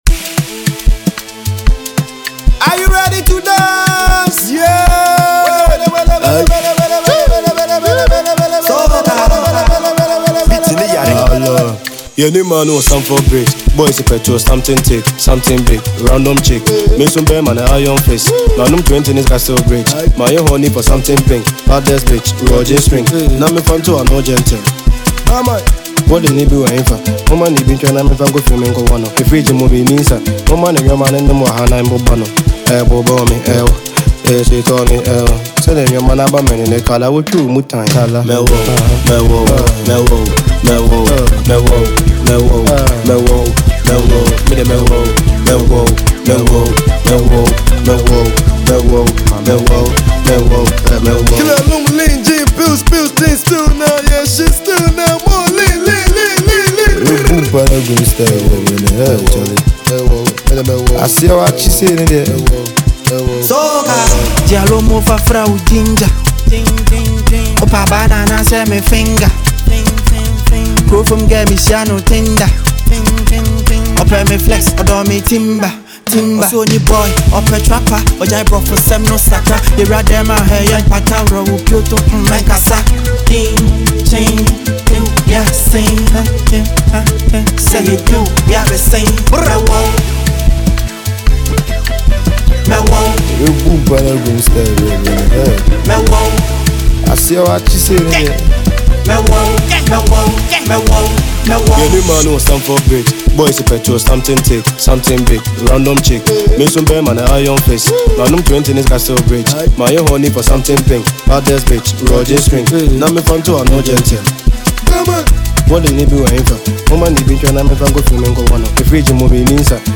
fast, precise, and full of attitude
Genre: Drill / Hip-Hop